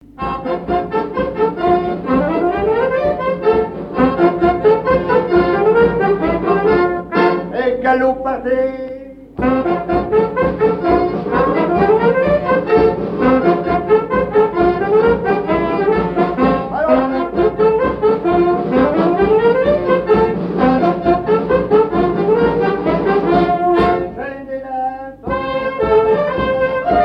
danse : quadrille : galop
Pièce musicale inédite